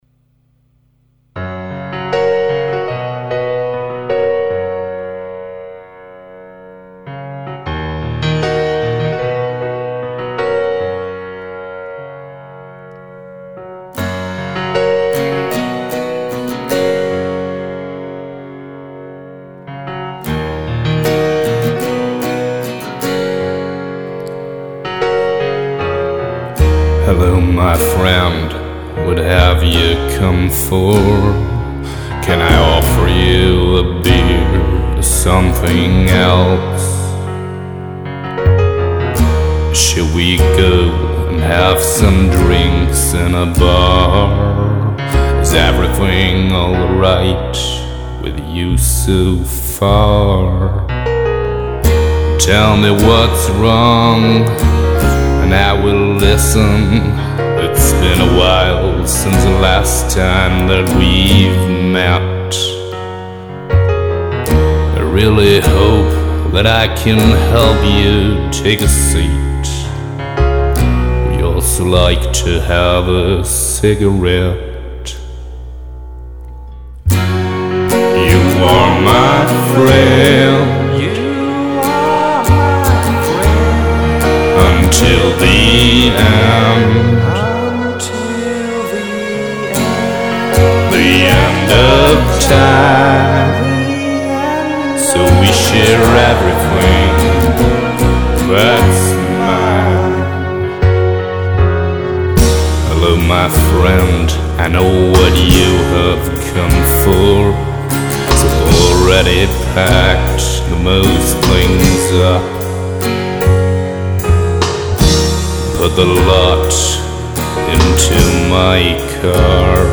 Schlagzeug und Gesang
Keys, Gitarre,  Mundharmonika